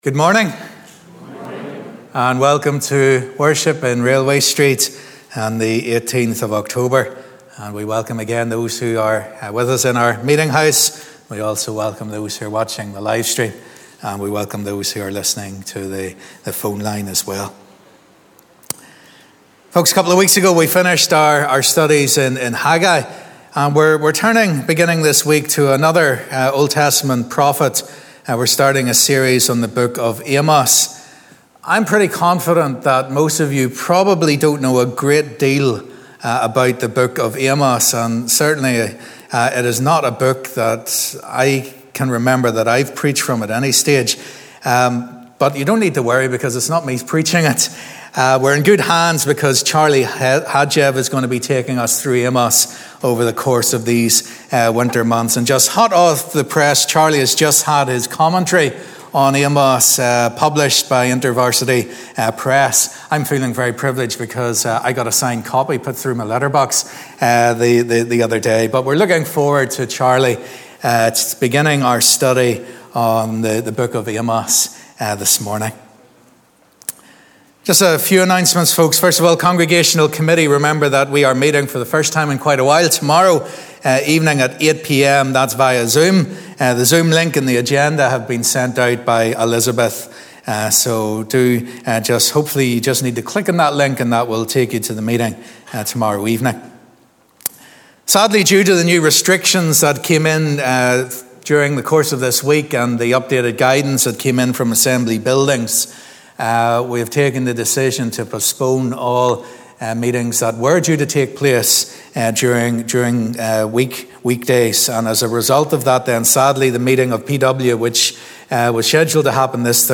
Sunday 18th October 2020 Live @ 10:30am Morning Service Amos 1-2 Audio will be available after the service.